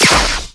laser1.wav